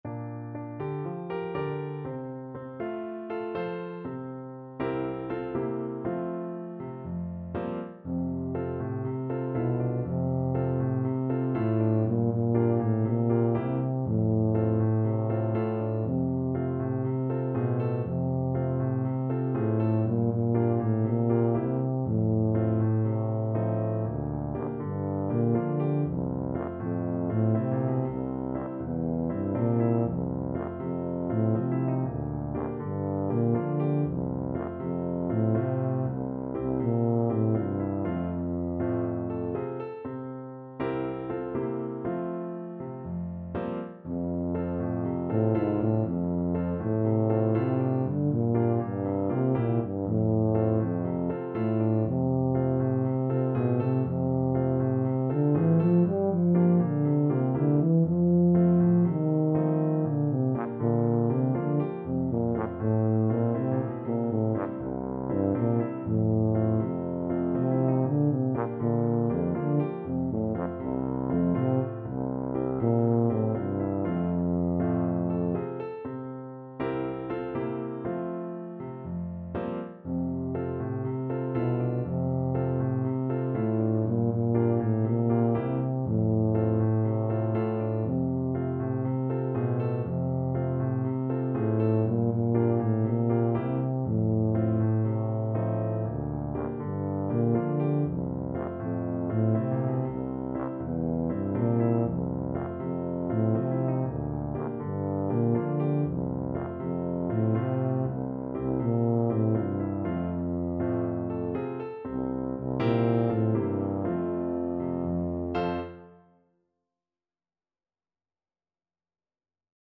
Tuba
4/4 (View more 4/4 Music)
F major (Sounding Pitch) (View more F major Music for Tuba )
Calypso = 120